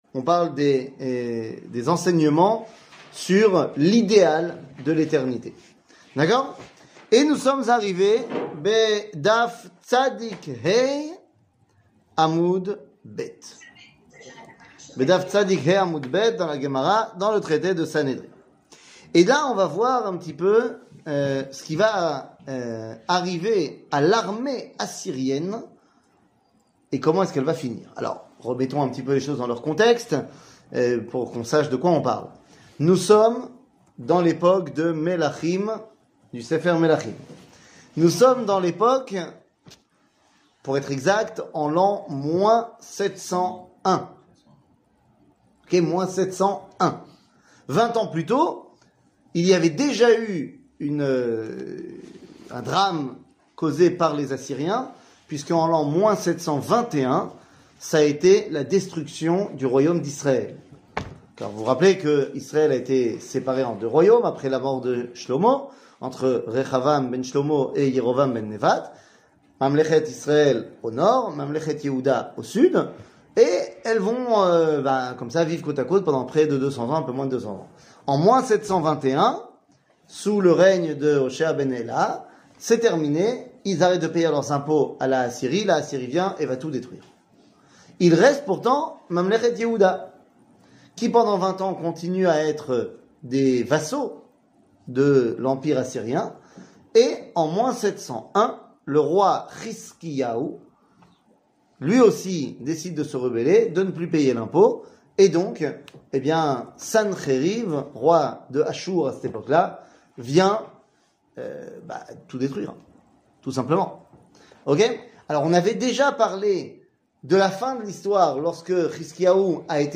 קטגוריה Perek Helek 00:51:42 Perek Helek שיעור מ 09 מאי 2022 51MIN הורדה בקובץ אודיו MP3